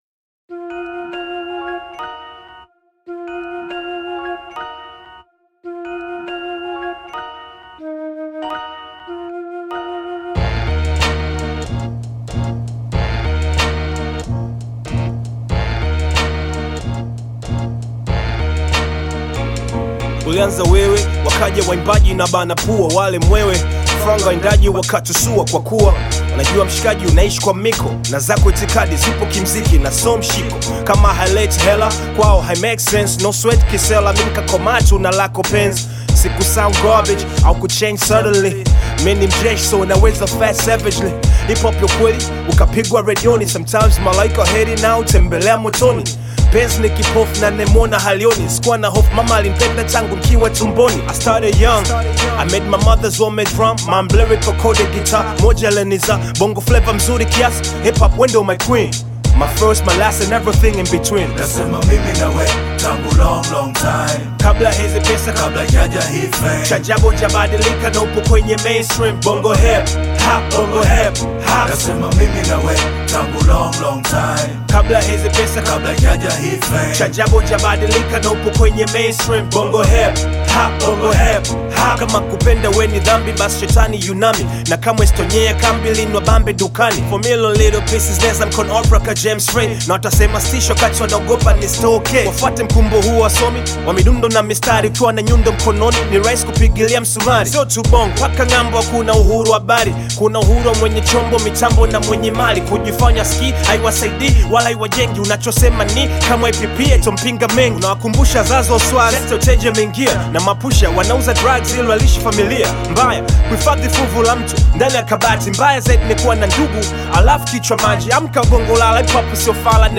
solo artist